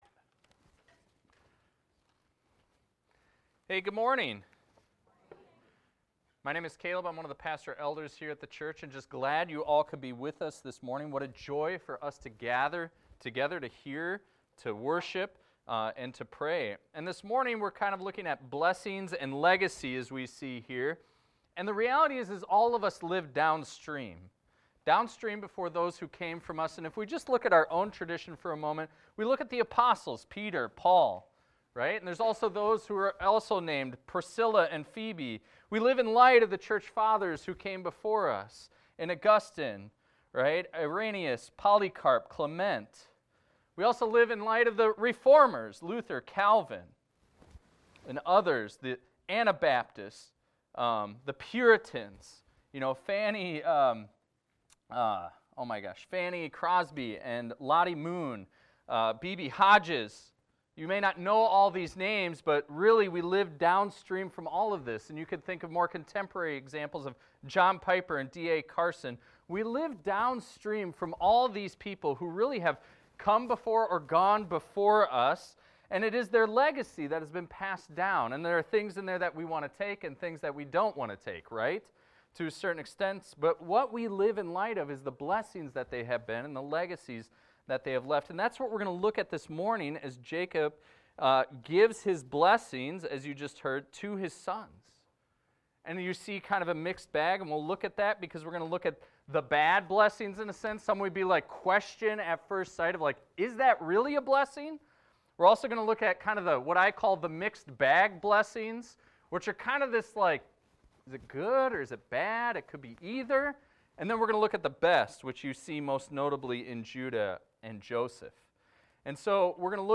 This is a recording of a sermon titled, "Blessings and Legacy."